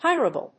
音節hir・a・ble 発音記号・読み方
/hάɪ(ə)rəbl(米国英語)/